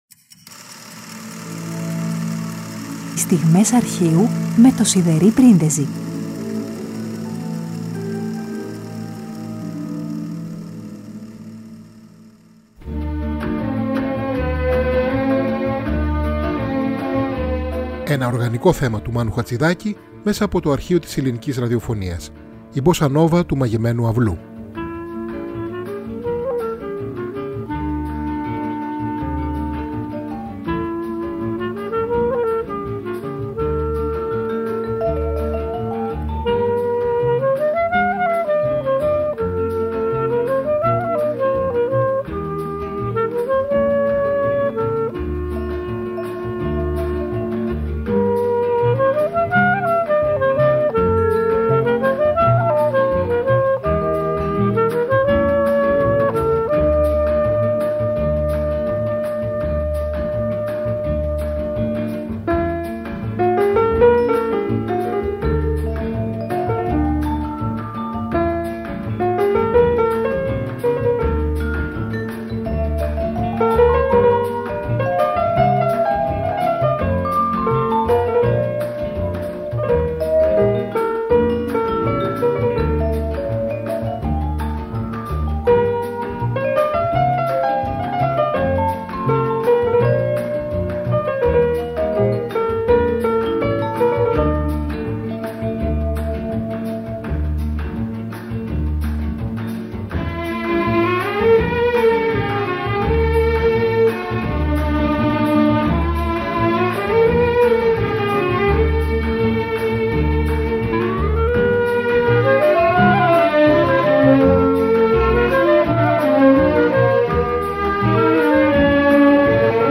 Ένα οργανικό θέμα